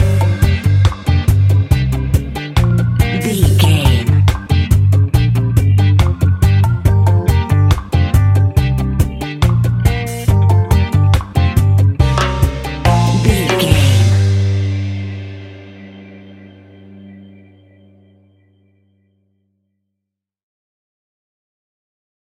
Classic reggae music with that skank bounce reggae feeling.
Aeolian/Minor
dub
laid back
chilled
off beat
drums
skank guitar
hammond organ
percussion
horns